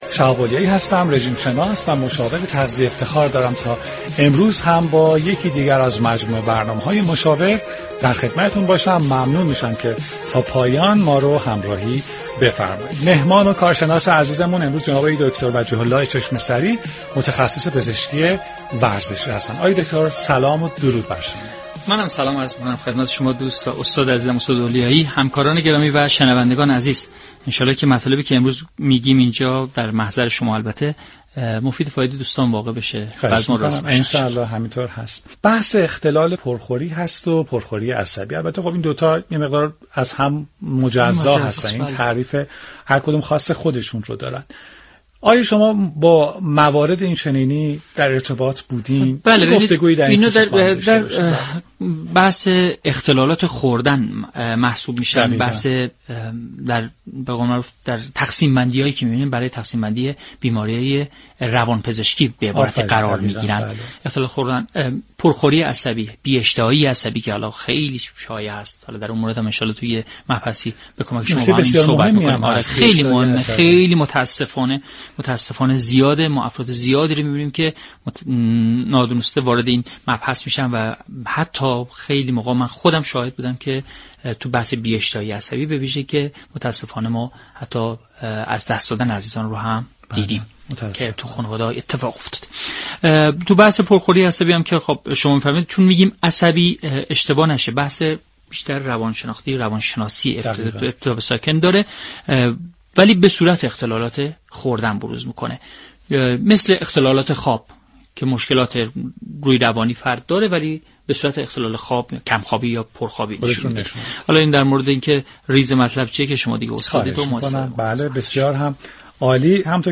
شما می توانید از طریق فایل صوتی ذیل شنونده بخشی از برنامه مشاور رادیو ورزش كه شامل صحبت های این متخصصان است؛ باشید.